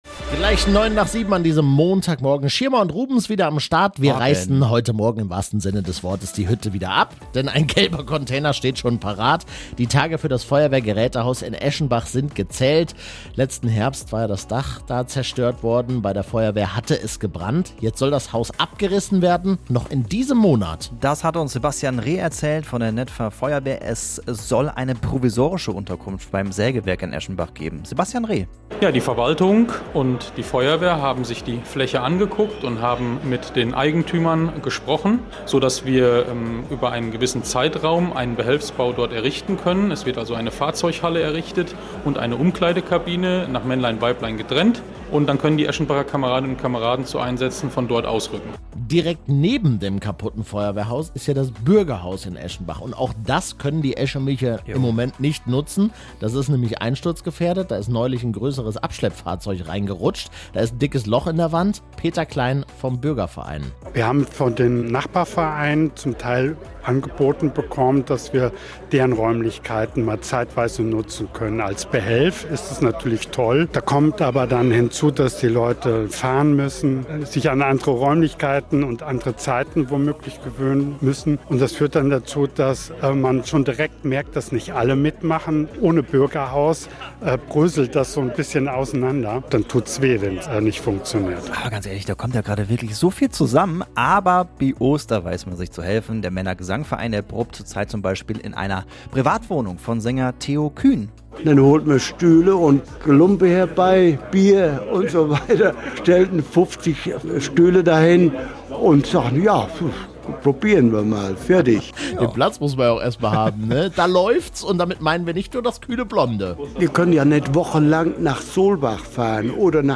Anzeige Beitrag: Radio Siegen "am Morgen" Anzeige play_circle play_circle Radio Siegen Update zum Feuerwehrgeräte- und Bürgerhaus download play_circle Abspielen download Anzeige